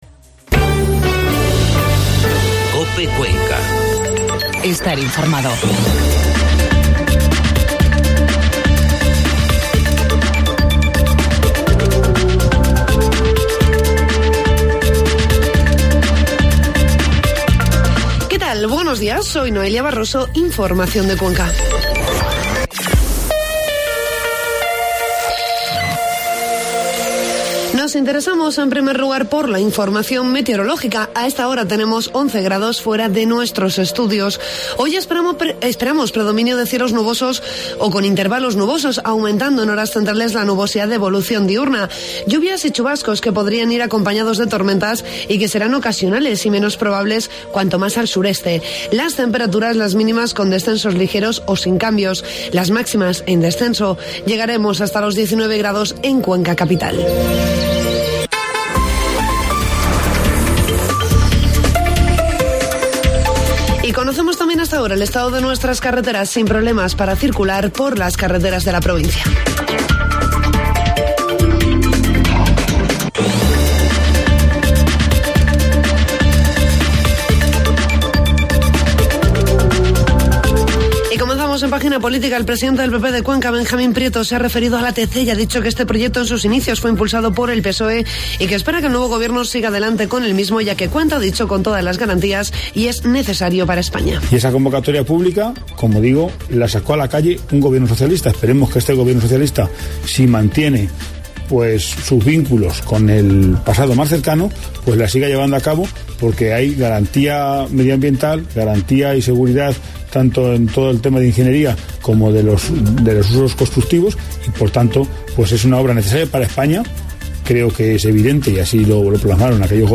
Informativo matinal COPE Cuenca 5 de junio